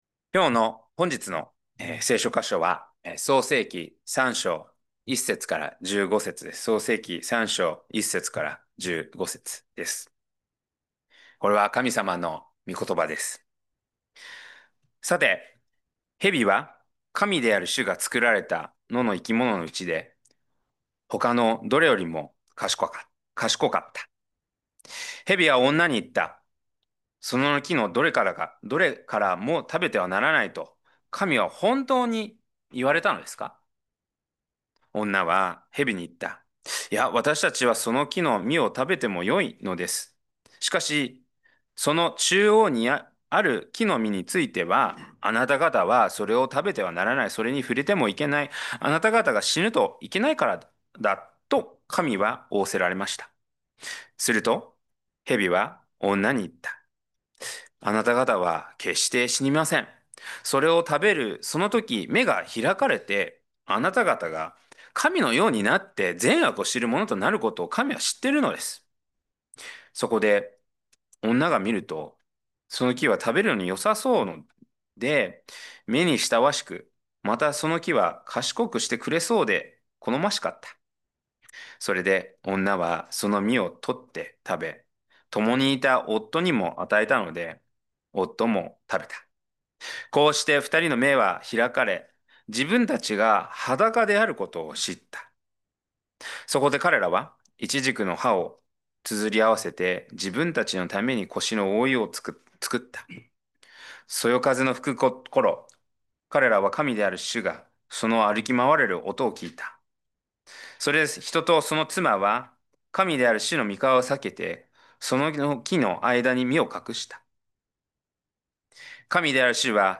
2025年10月12日礼拝 説教 「恥を覆う十字架」